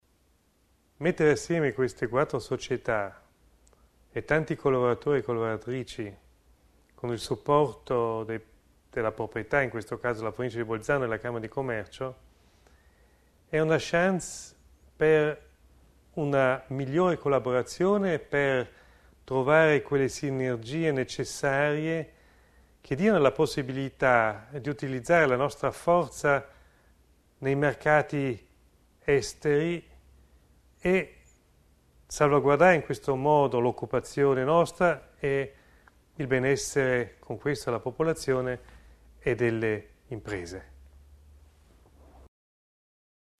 Intervista Michl Ebner sull'accordo quadro per l' Azienda speciale - Provincia e Camera commercio (Audio)